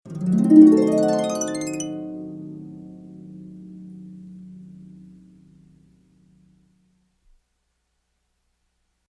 Звук волшебного возникновения золотой рыбки